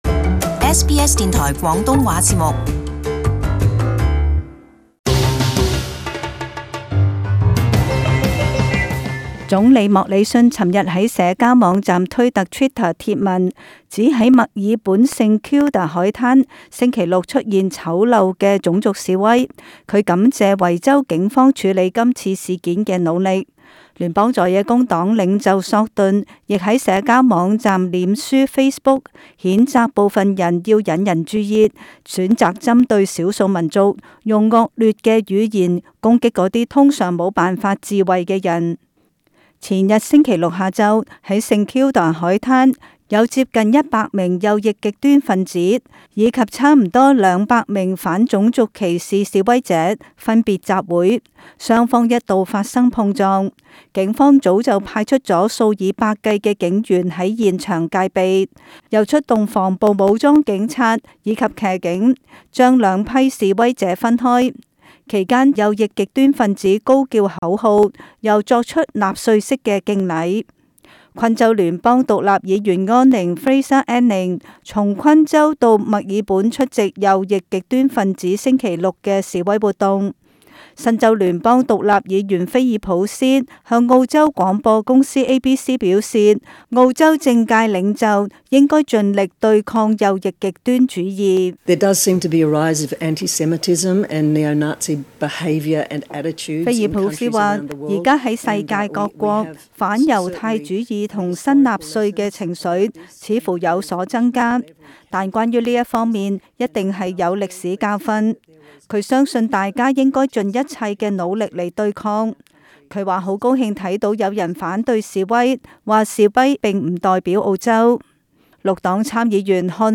Source: AAP SBS廣東話節目 View Podcast Series Follow and Subscribe Apple Podcasts YouTube Spotify Download (9.04MB) Download the SBS Audio app Available on iOS and Android 在前日星期六墨爾本St.